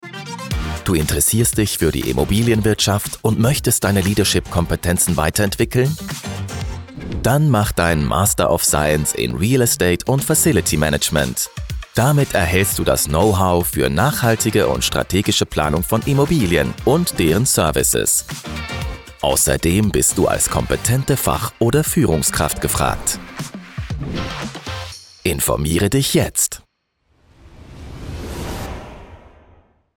Werbung Hochdeutsch (CH)